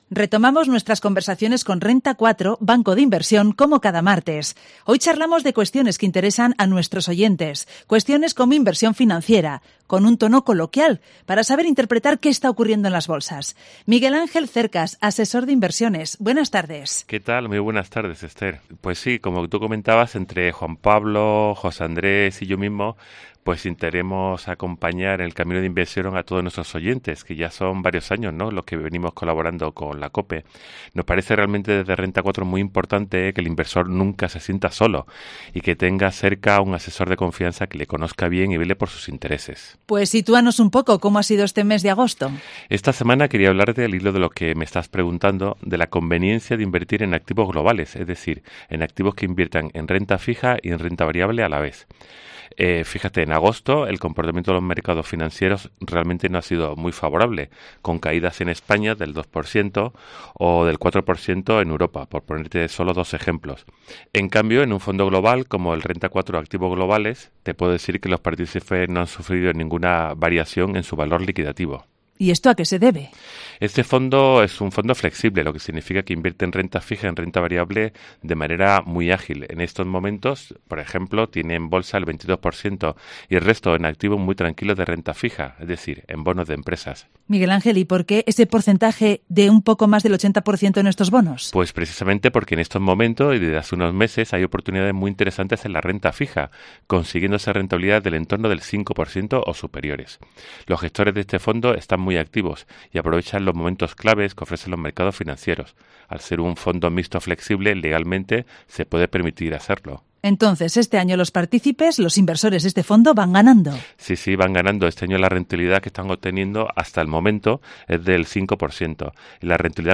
Después de las vacaciones de verano, retomamos en Cope León nuestras conversaciones con Renta 4, banco de inversión, para charlar de cuestiones de inversión financiera, con un tono coloquial, para que nuestros oyentes sepan interpretar qué está ocurriendo en las bolsas.